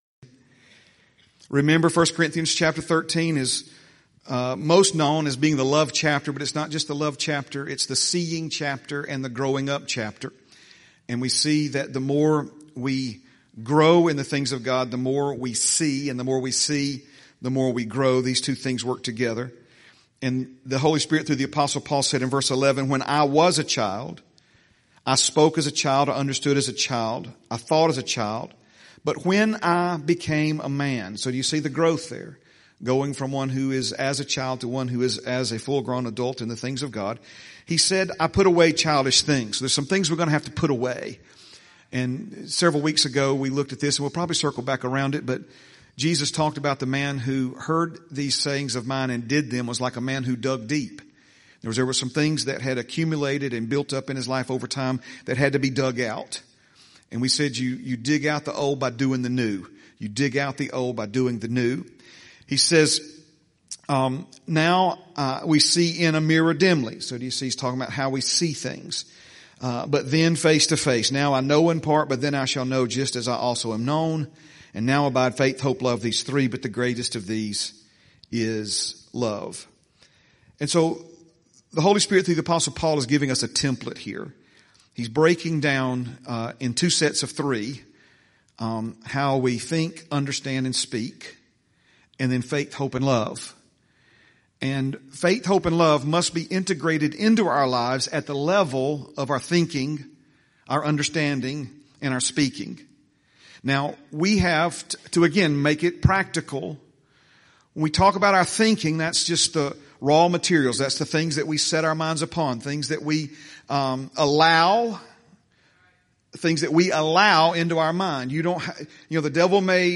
3/1/26 Sunday - Sunday Morning Message